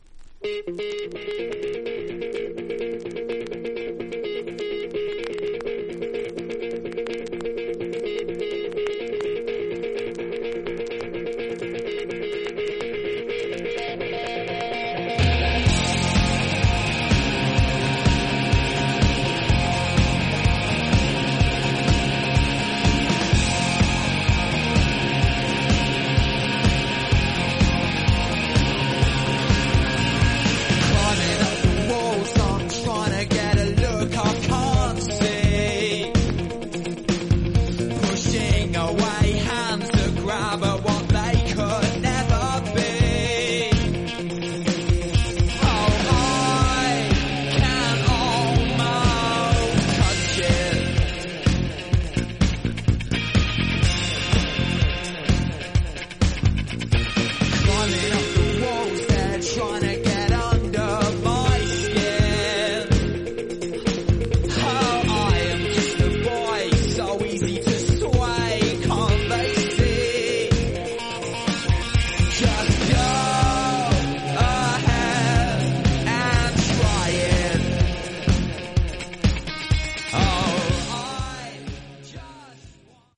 そのほかの盤面も薄いスリキズがそこそこあり所々チリパチノイズ。
実際のレコードからのサンプル↓ 試聴はこちら： サンプル≪mp3≫